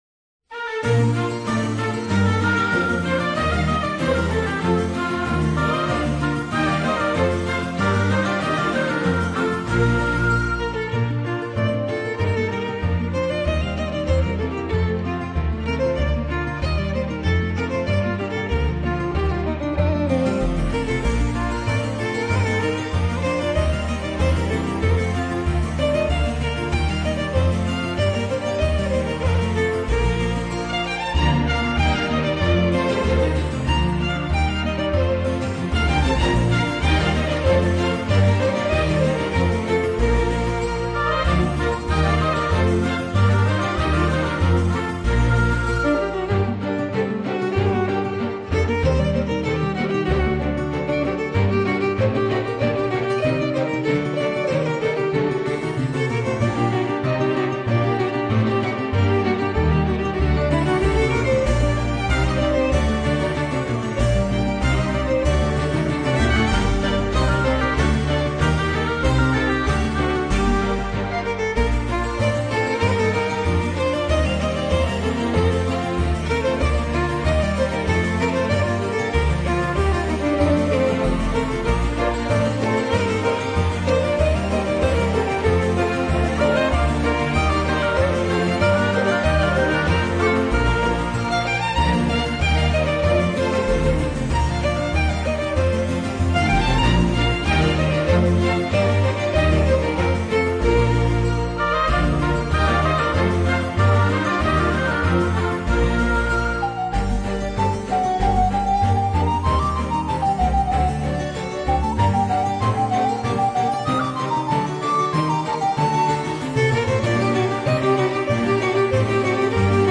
Жанр: New Age, NeoClassical